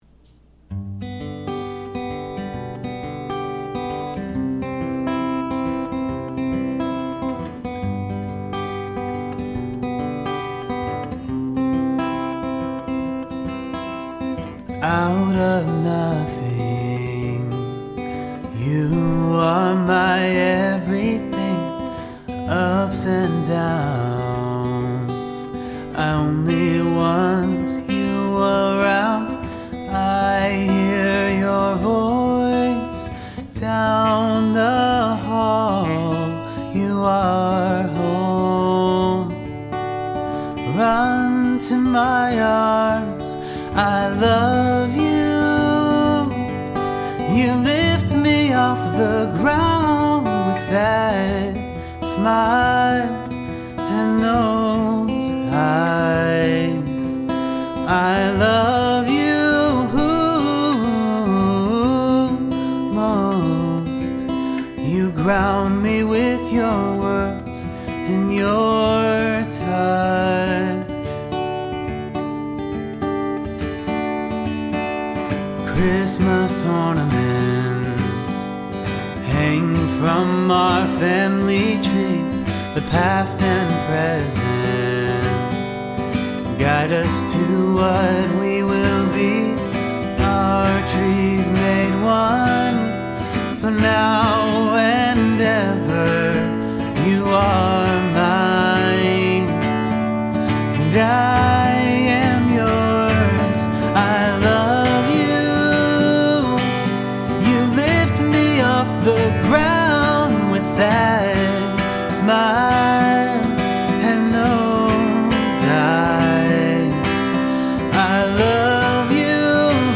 I Love You Most - The wedding song (will be shortened for the actual musical probably).